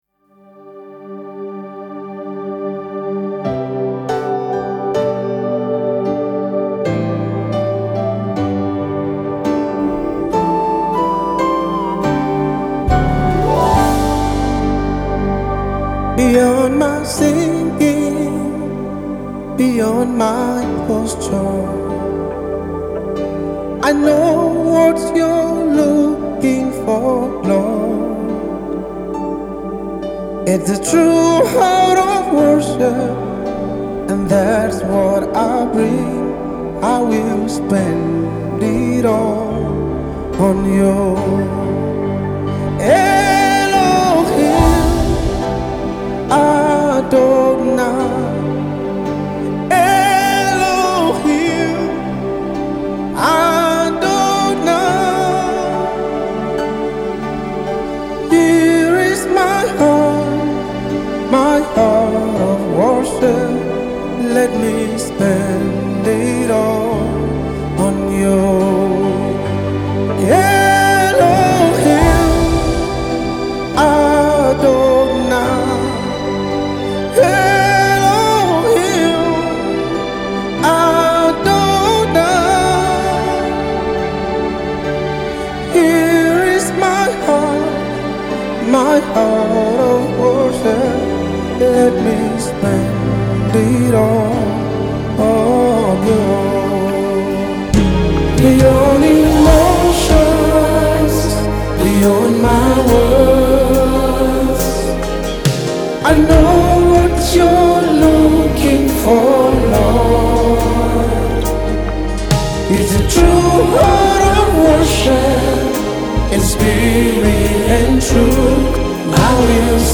With a heart full of worship and anointed vocals